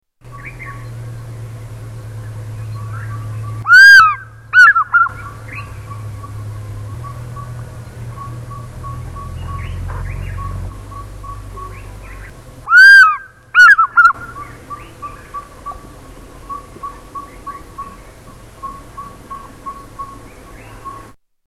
African Fish Eagle
Tags: Science and Nature Wildlife sounds Bristish Animals British Wildlife sounds United Kingdom